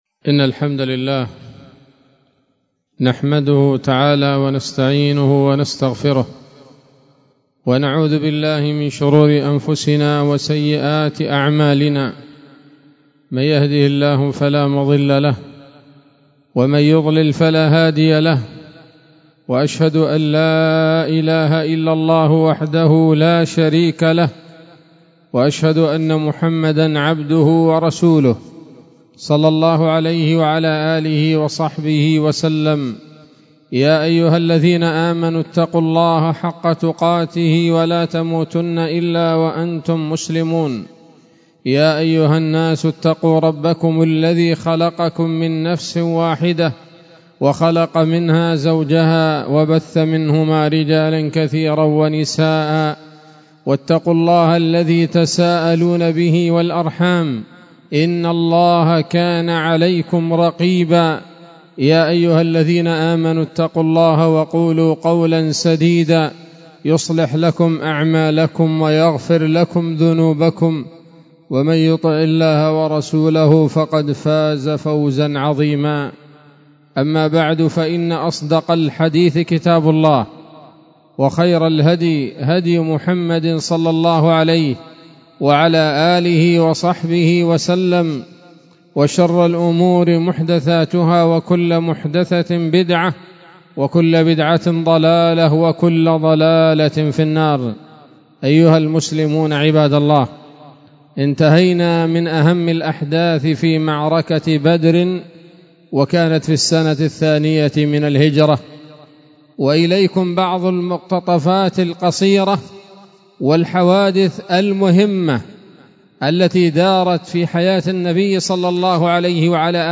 خطبة جمعة بعنوان: (( السيرة النبوية [16] )) 20 شعبان 1445 هـ، دار الحديث السلفية بصلاح الدين